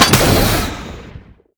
JackHammer_3p_02.wav